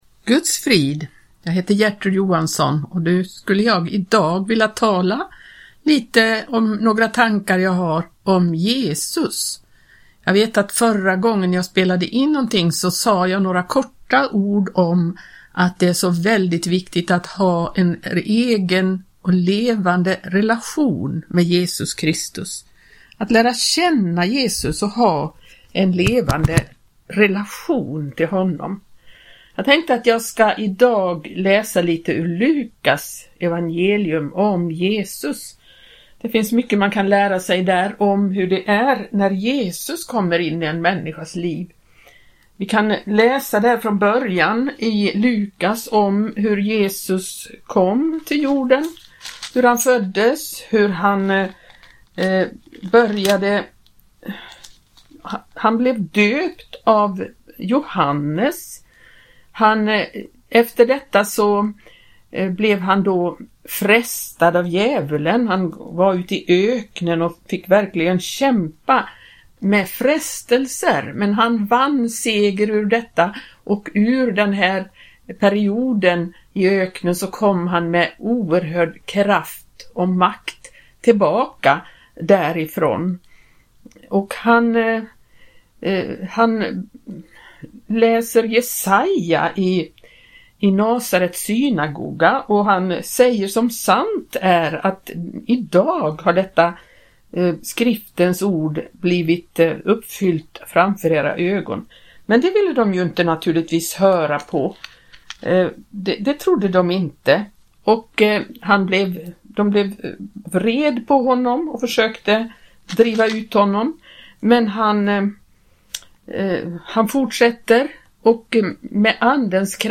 talar i Radio Maranata